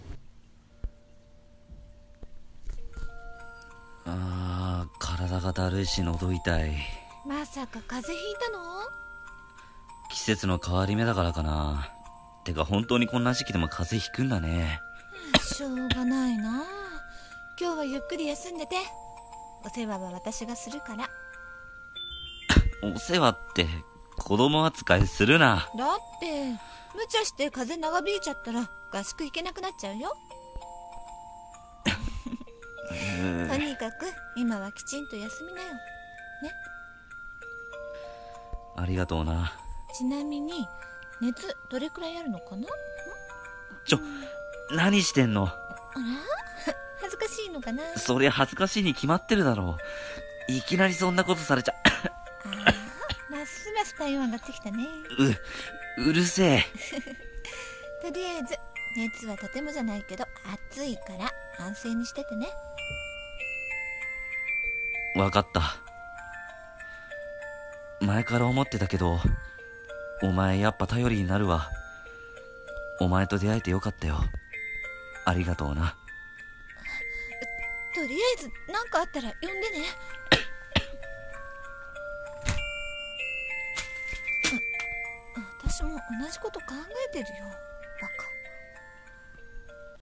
【コラボ用声劇】風邪をひいてしまった友人 【男女コラボ歓迎】